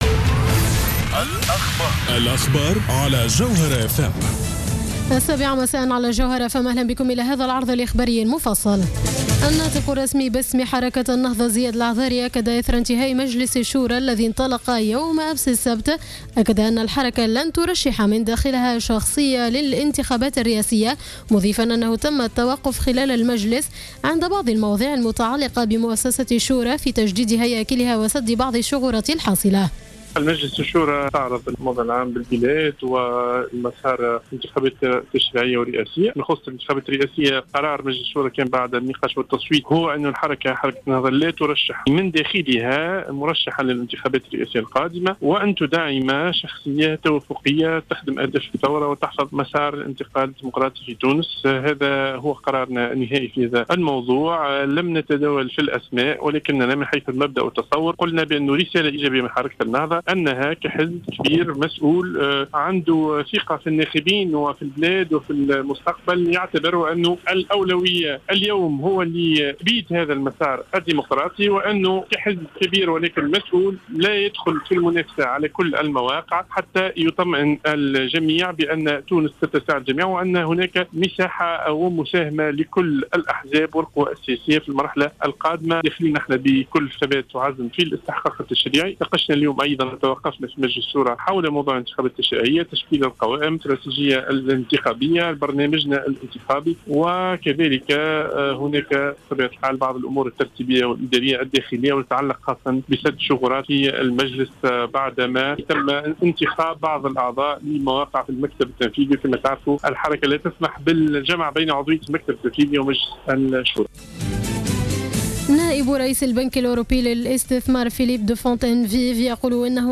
نشرة أخبار السابعة مساء ليوم الأحد 07-09-14